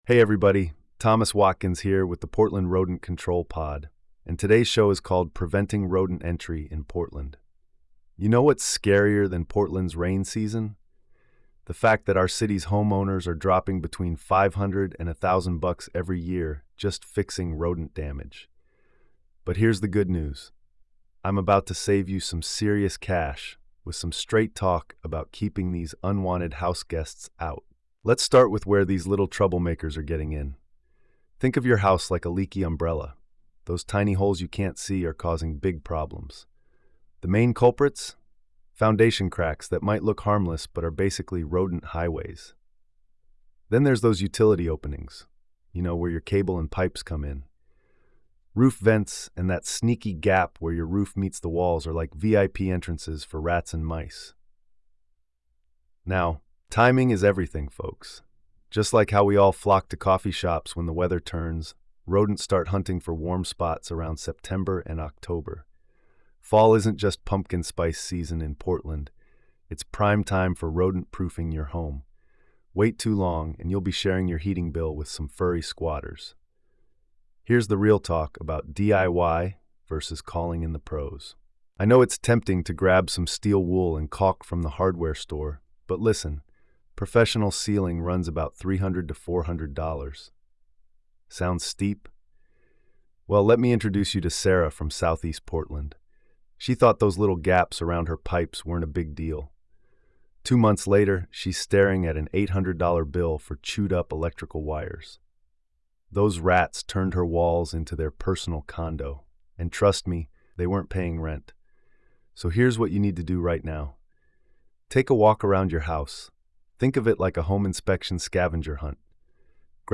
pest control expert